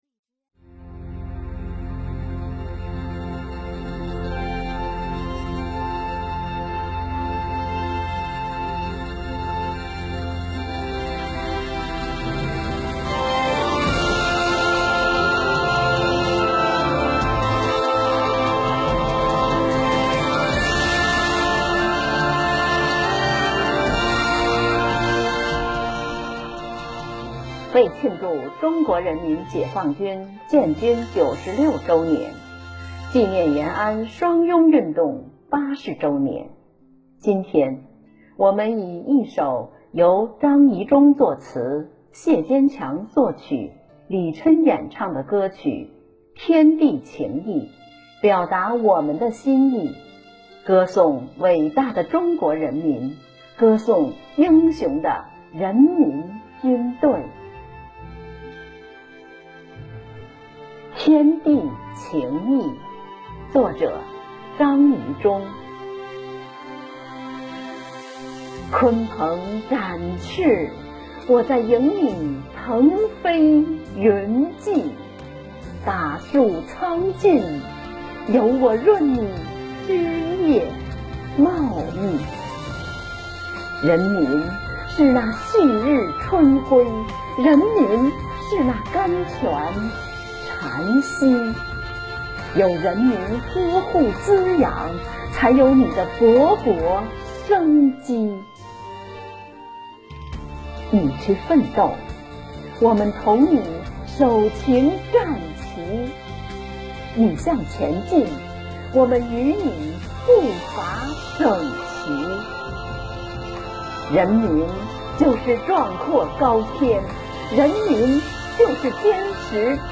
退役军人：诗朗诵十歌曲《天地情义》 – 北欧华人网
（《北欧华人报》北欧国际新闻中心记者文化报道）8月1日，为庆祝中国人民解放军成立九十六周年，纪念延安双拥运动八十周年，北京古塔读书会晚在北京隆重举办线上朗诵会。